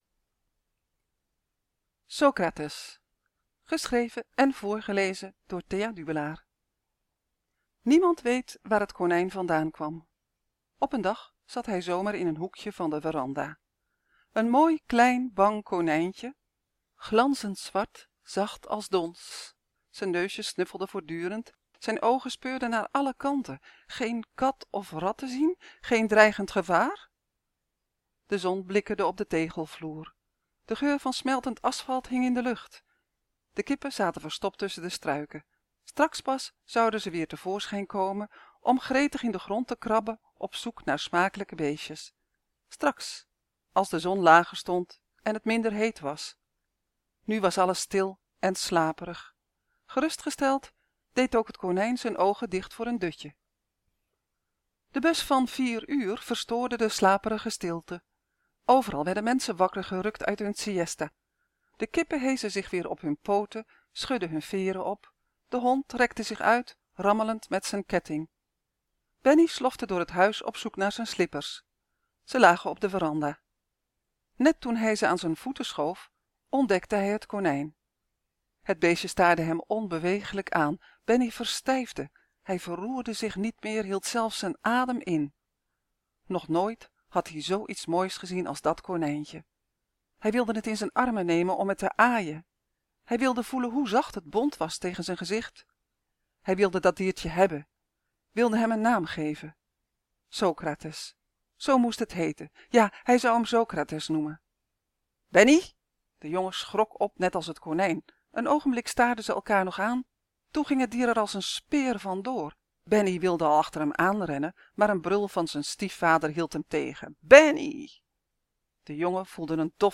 SOCRATES Dit verhaal is het begin van het boek ‘Socrates’. Het gaat over Benny, die op een Antilliaans eiland woont.